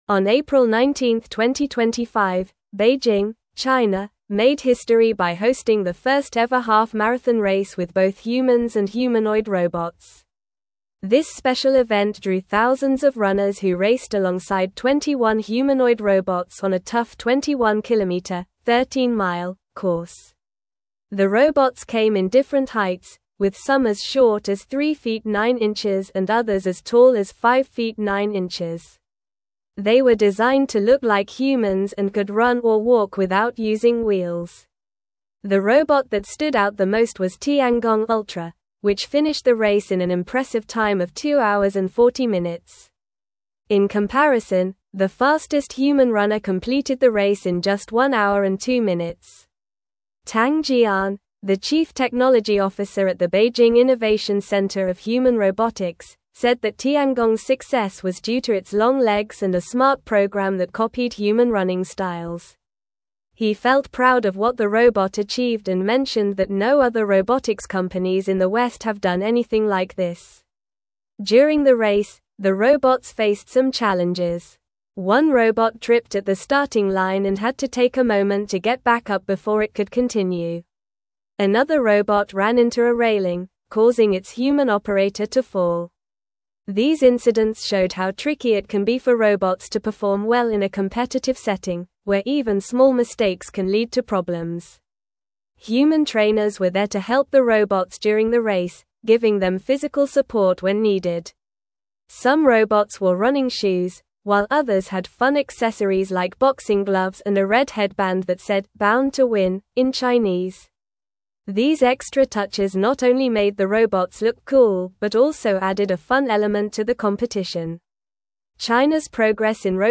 Normal
English-Newsroom-Upper-Intermediate-NORMAL-Reading-China-Hosts-Historic-Half-Marathon-with-Humans-and-Robots.mp3